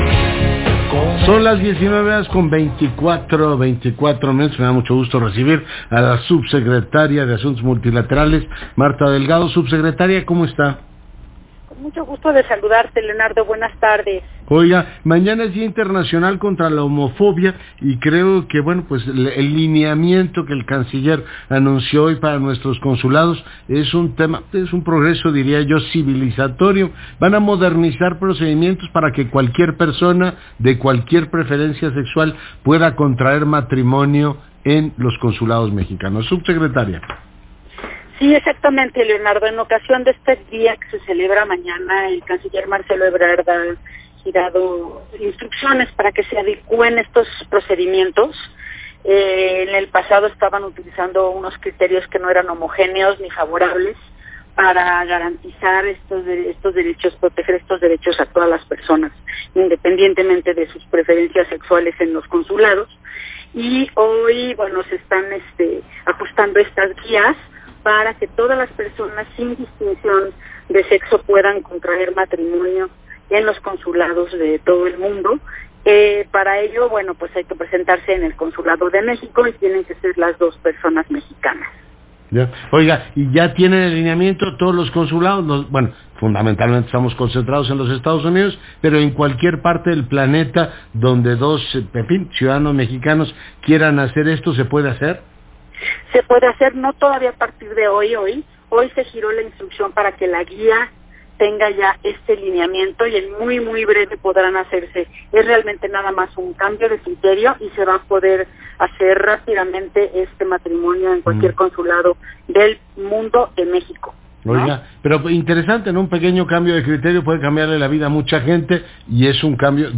[Audio] Entrevista en ADN 40 con Leonardo Curzio sobre matrimonio igualitario en Consulados